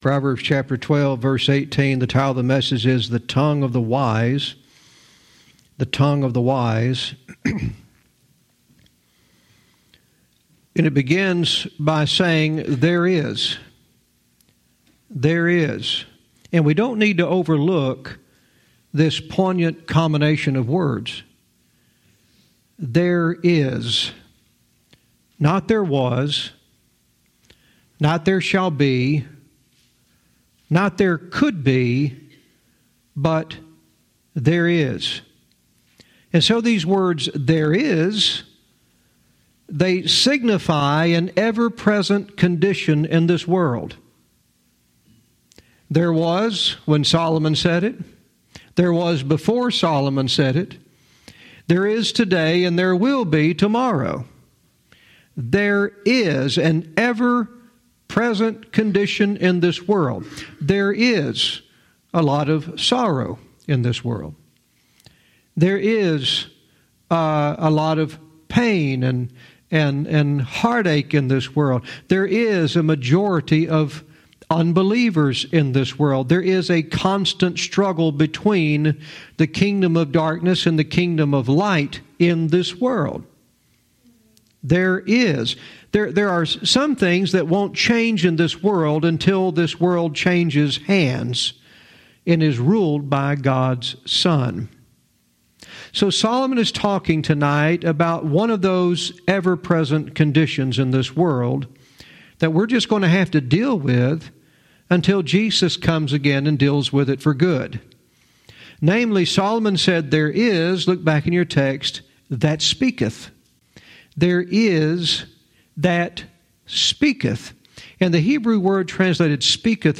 Verse by verse teaching - Proverbs 12:18 "The Tongue of the Wise."